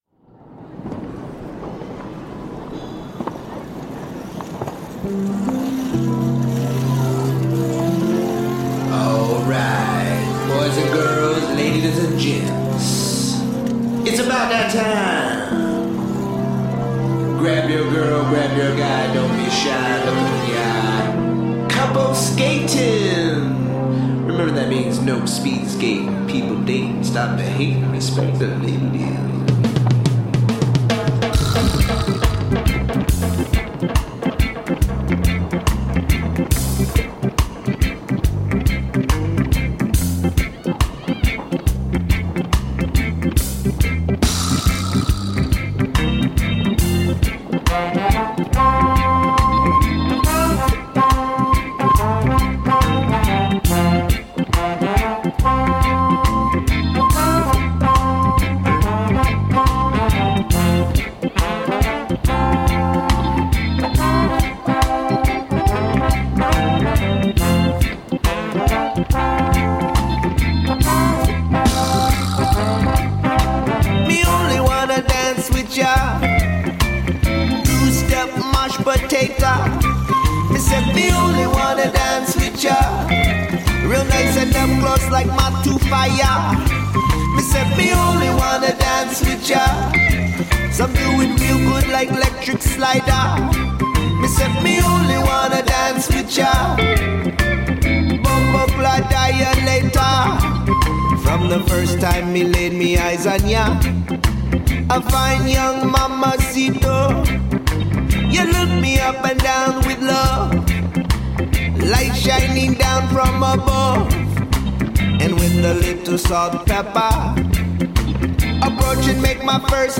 Rootsy, dubby, reggae sounds from the rainy city.
Tagged as: World, Reggae, Chillout